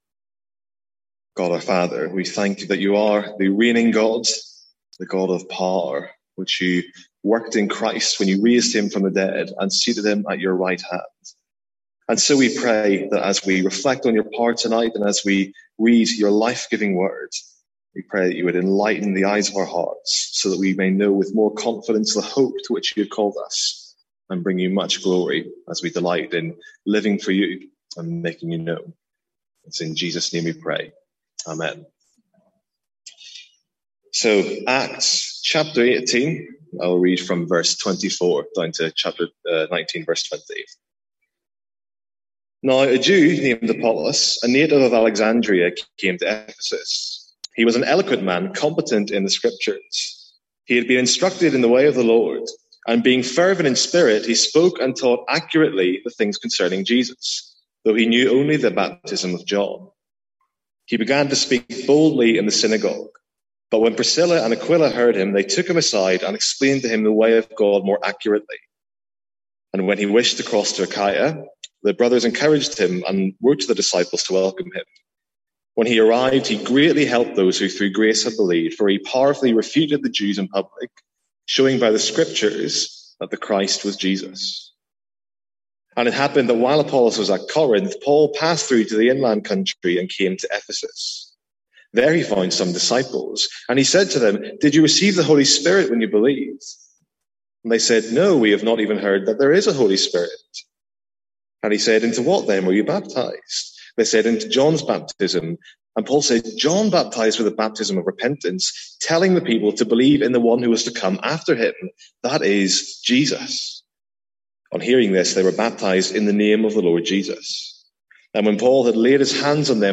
Sermons | St Andrews Free Church
From our evening series in Acts.